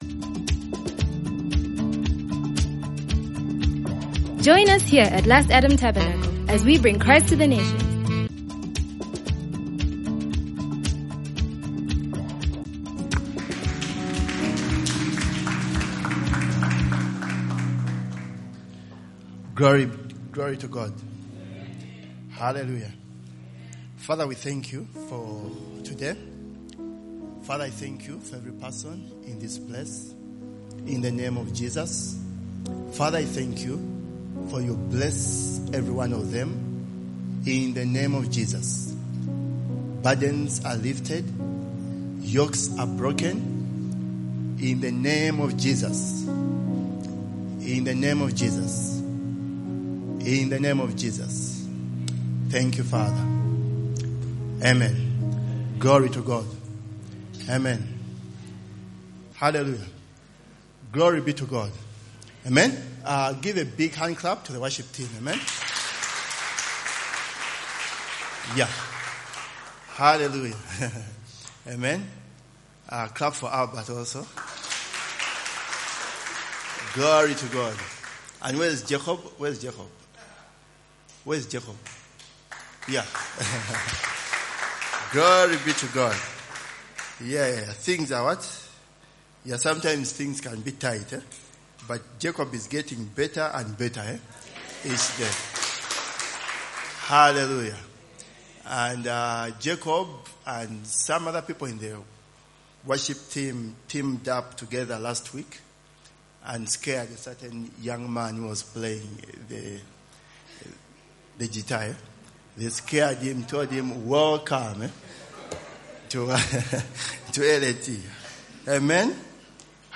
Promise Keeper. A sermon